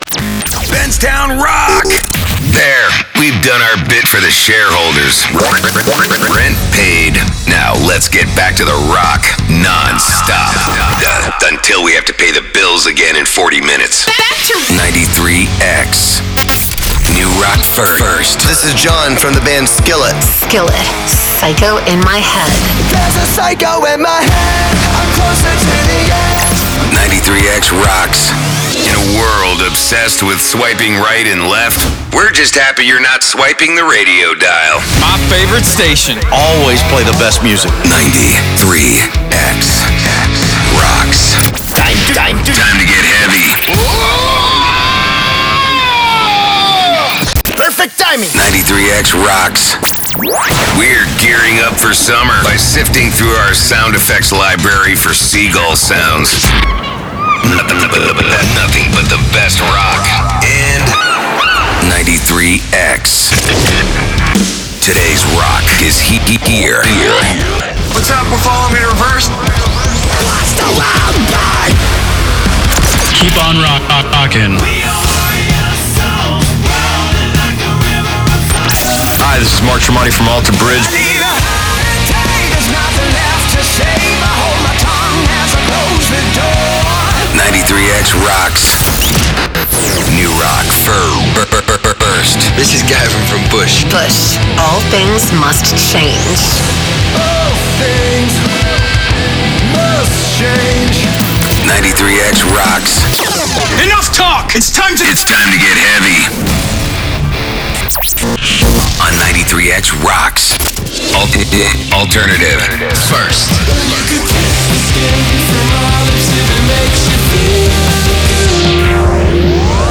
Rock-Composite-June-2023-1.wav